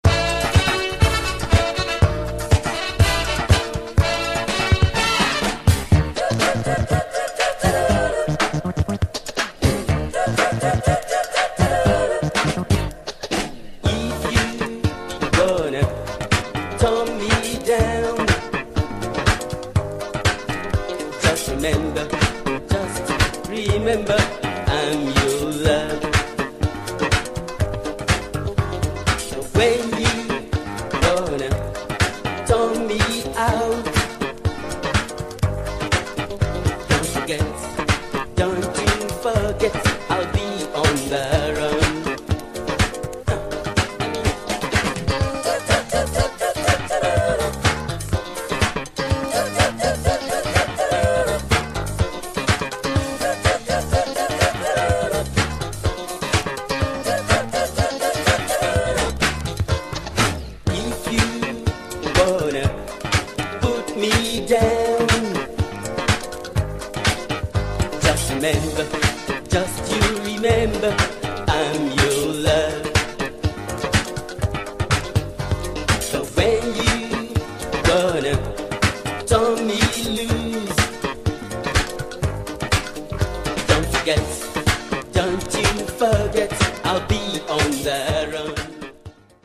Killer Nigerian disco / boogie funk.